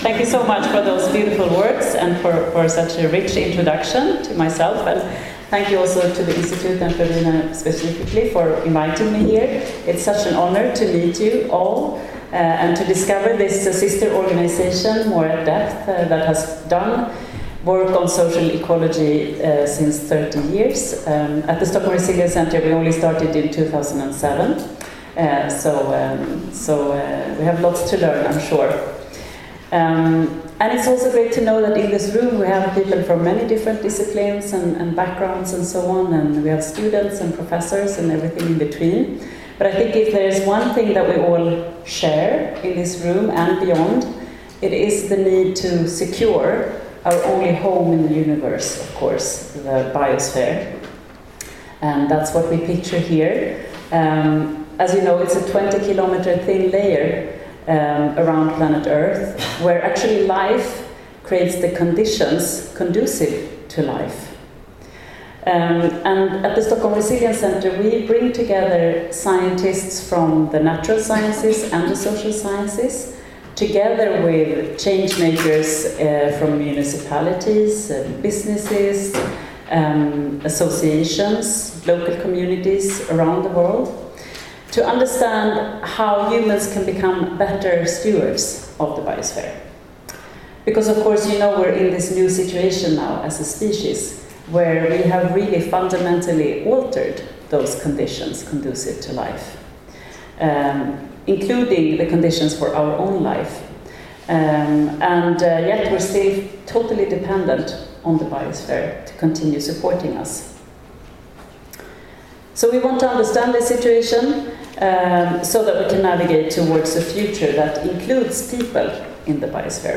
In der ISOE-Lecture befasst sie sich mit experimentellen Wegen, über die einflussreiche Akteure für nachhaltiges Handeln erreicht werden können. In ihrem Vortrag zeigt sie Beispiele dafür auf und erläutert, welche Lehren sich im Umgang mit komplexen sozial-ökologischen Systemen ziehen lassen.